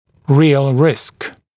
[rɪəl rɪsk][риэл риск]реальный риск, действительный риск